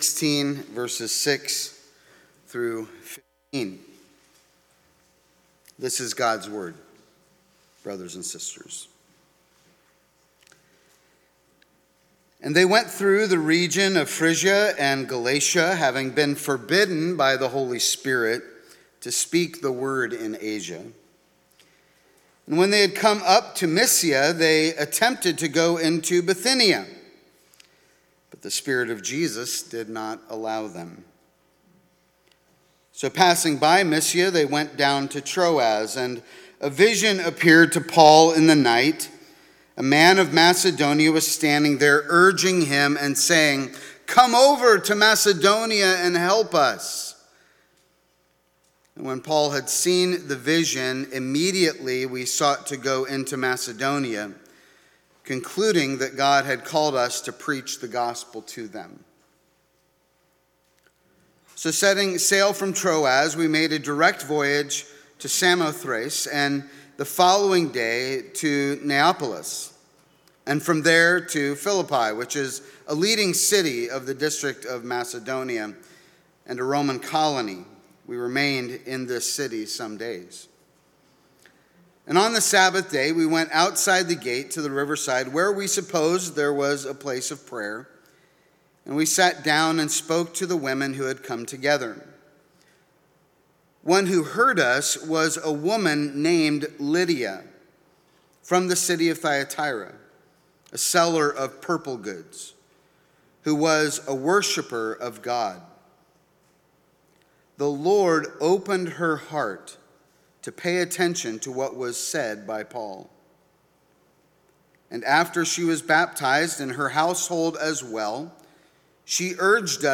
Sermons | New Life Presbyterian Church of La Mesa